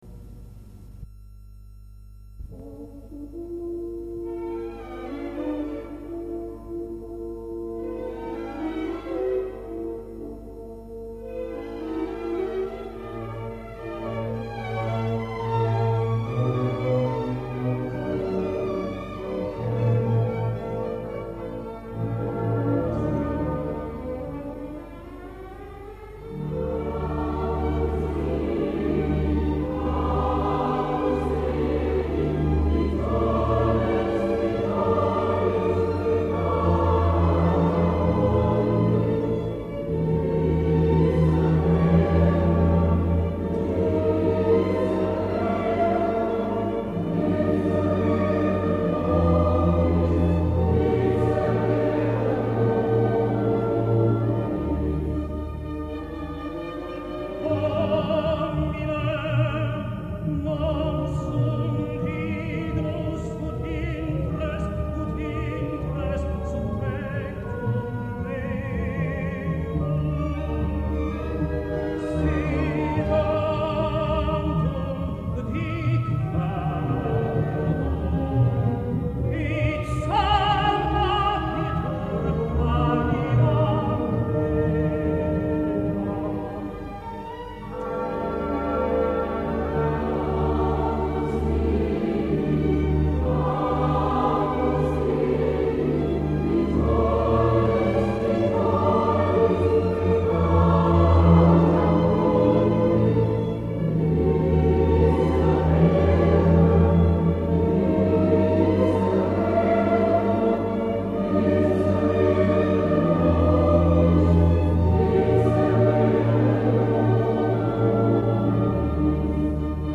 A l'invitation de ACJ Macon, concert  "Stiftskantorei & Kammerorchester Neustadt" St-Pierre MACON
Extraits du concert